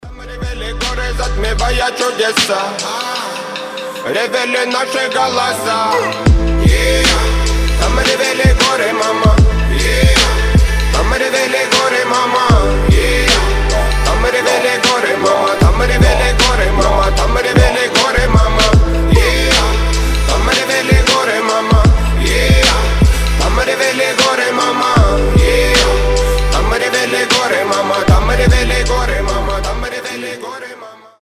Рэп рингтоны
Хип-хоп , Грустные
Атмосферные